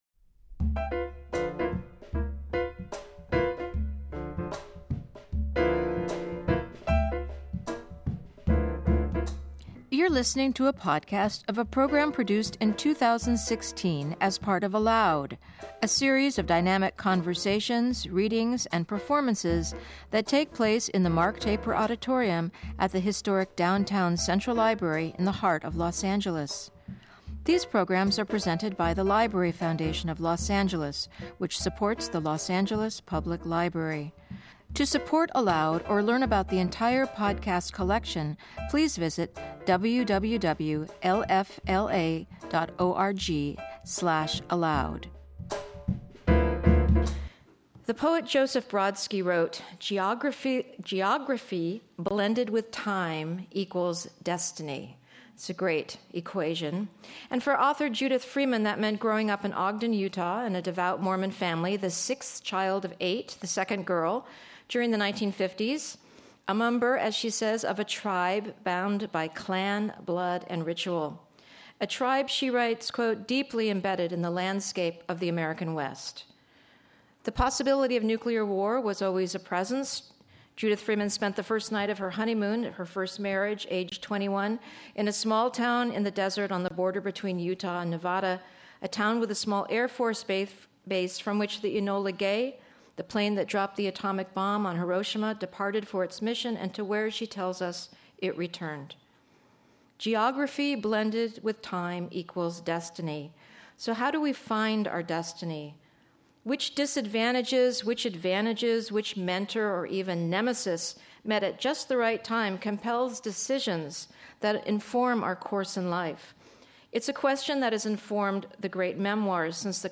In Conversation With Novelist